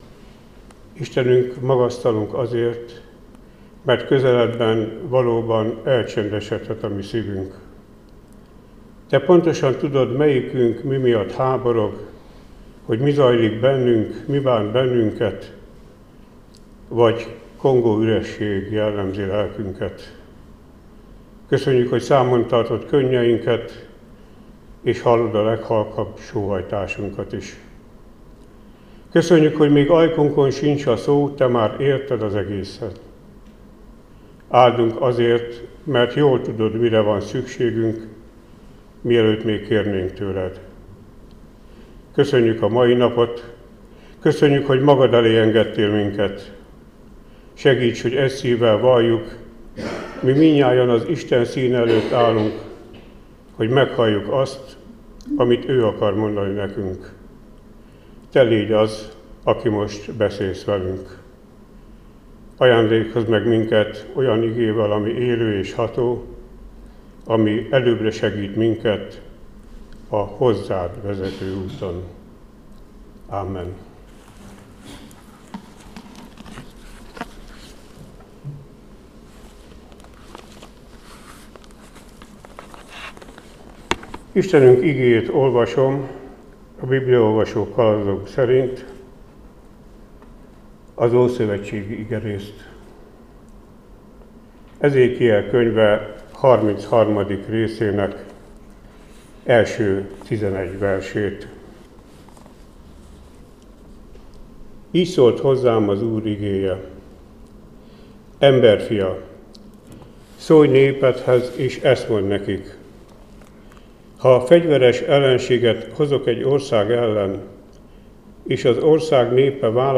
Áhítat, 2025. április 1.